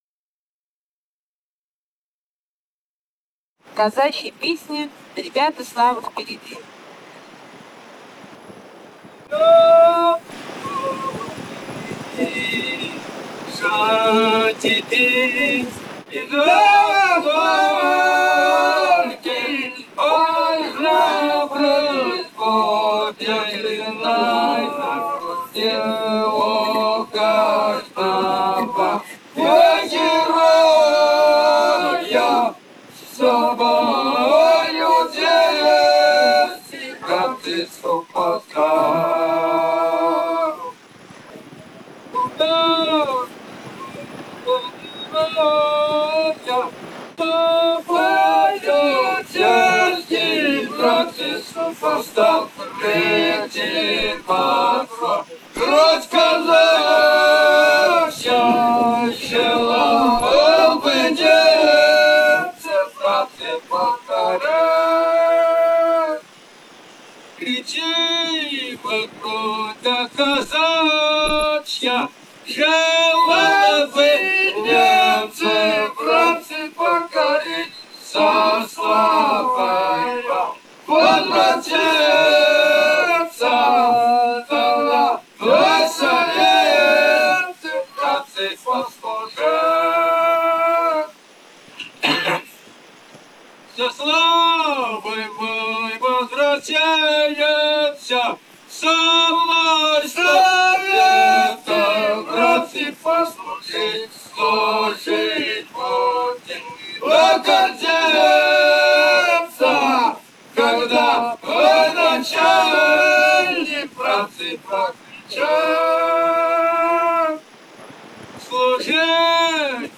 полевые материалы
«Ребята, слава впереди» (казачья походная).
Бурятия, с. Желтура Джидинского района, 1966 г. И0903-19